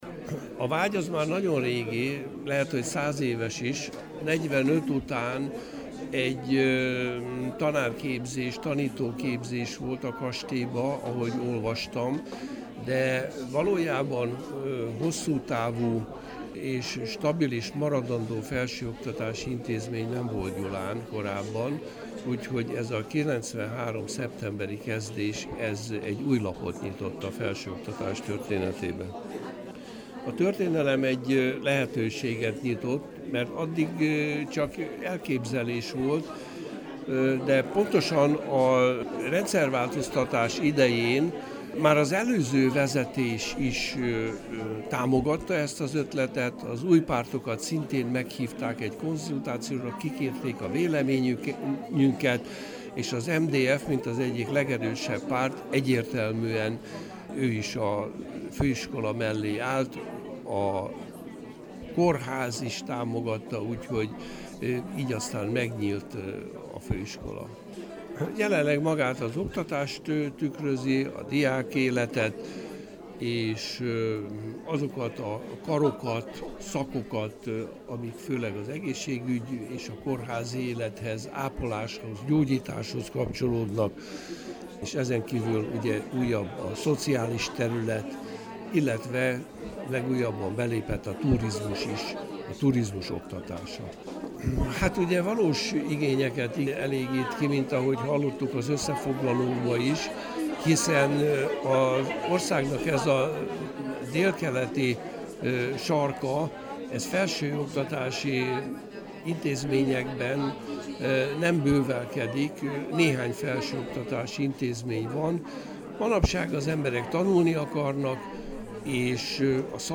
A megnyitó során gondolatokat osztott meg a hallgatósággal a gyulai felsőoktatás elindításáról